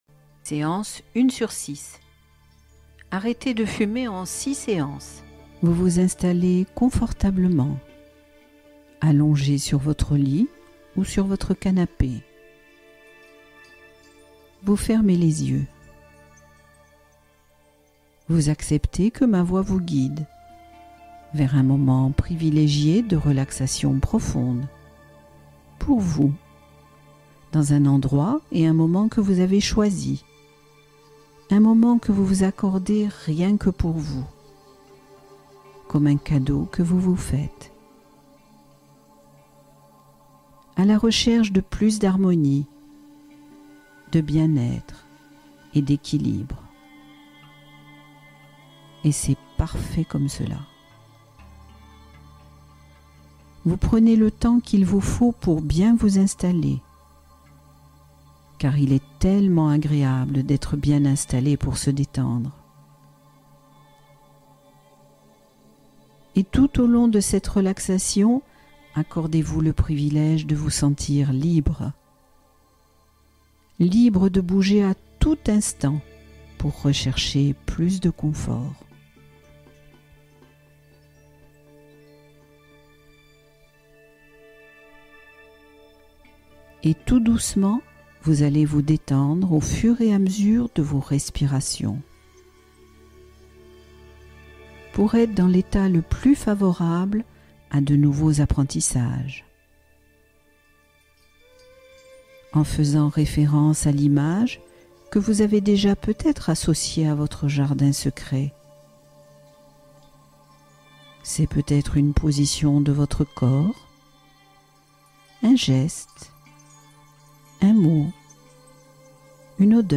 Gérer ses émotions : séance guidée d’apaisement